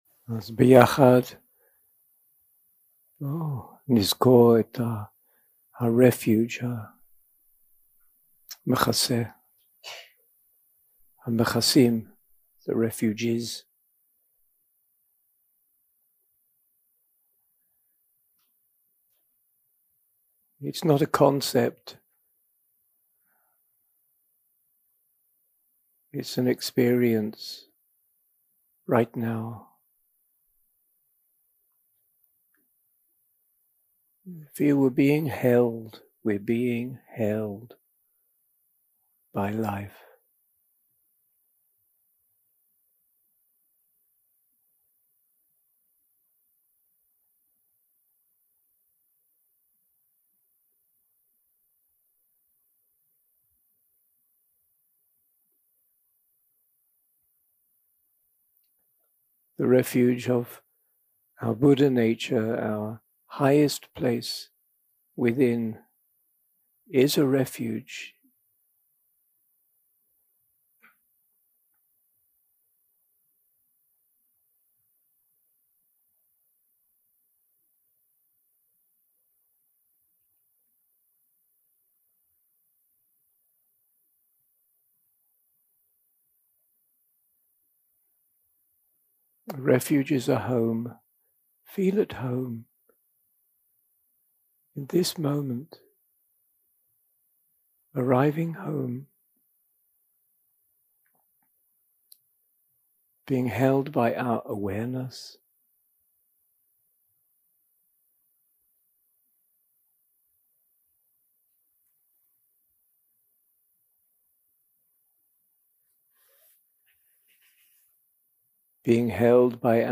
יום 2 – הקלטה 3 – צהריים – מדיטציה מונחית - Welcoming the Breath 0:00 0:00 סוג ההקלטה: Dharma type: Guided meditation שפת ההקלטה: Dharma talk language: English